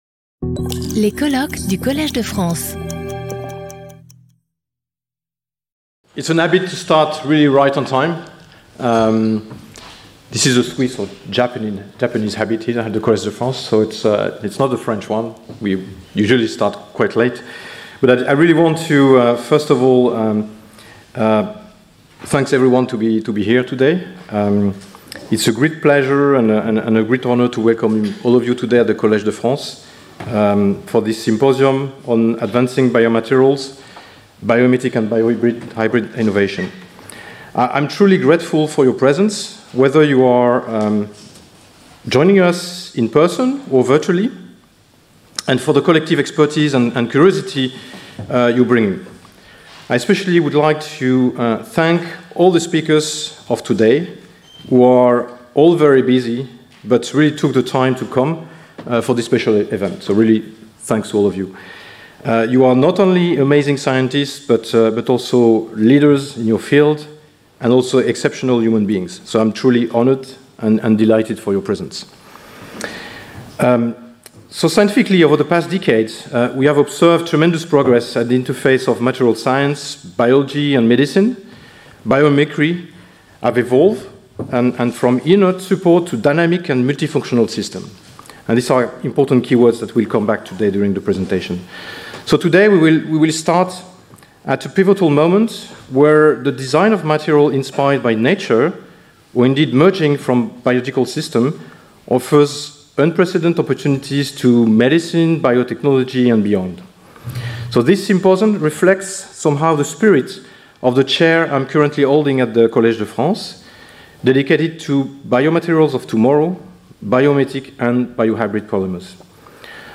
Symposium 6 Jun 2025 09:00 to 09:10